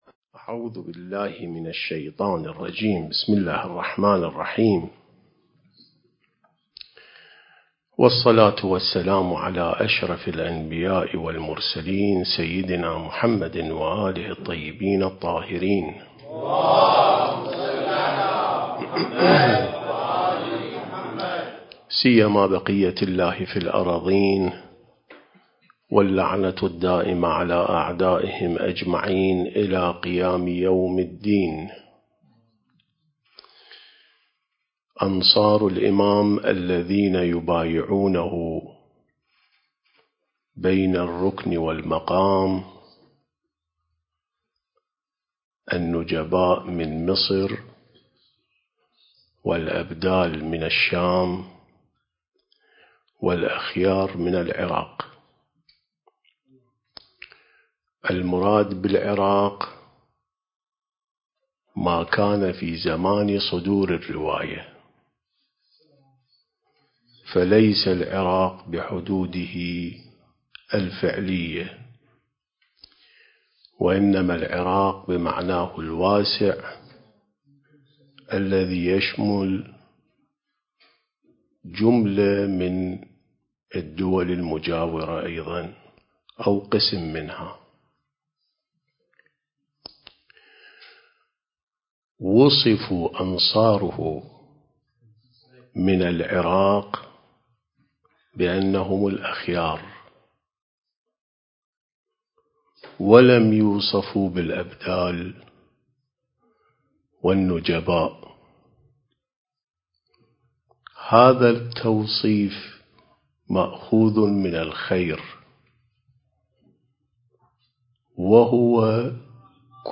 سلسلة محاضرات عين السماء ونهج الأنبياء